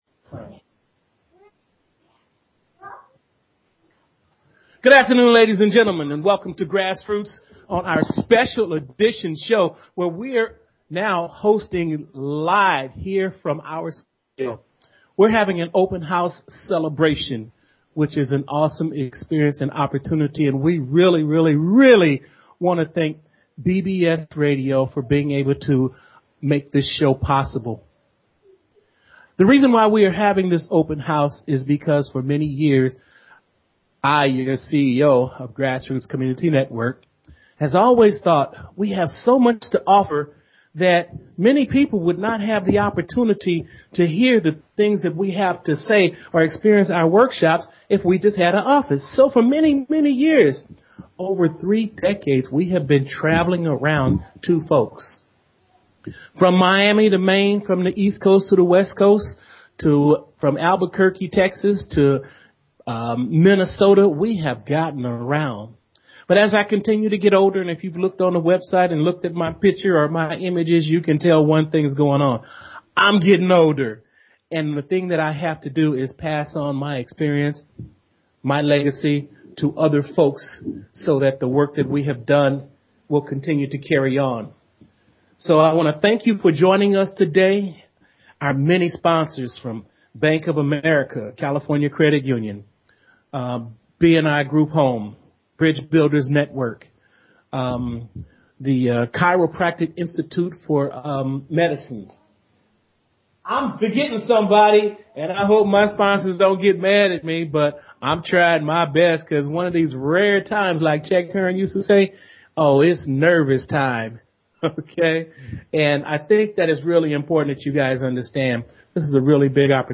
Talk Show Episode, Audio Podcast, Grassroots_Talks and Courtesy of BBS Radio on , show guests , about , categorized as